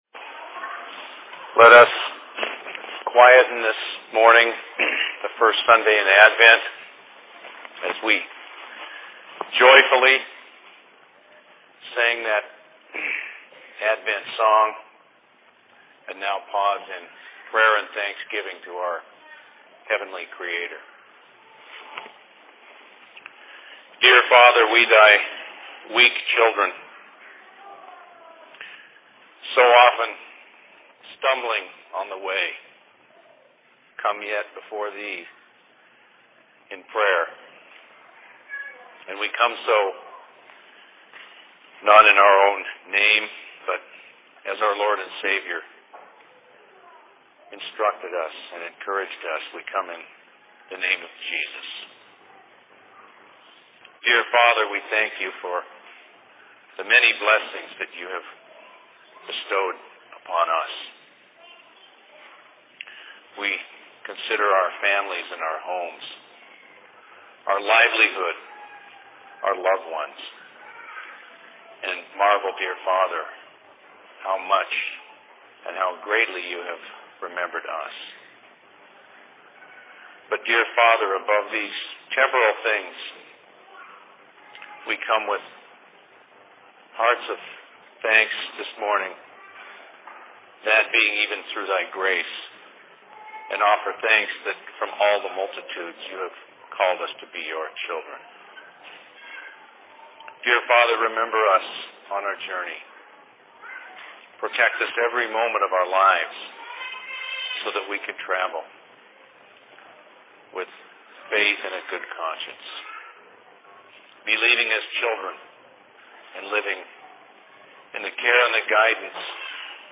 Thanks Giving services/Sermon in Minneapolis 27.11.2005
Sermon
Location: LLC Minneapolis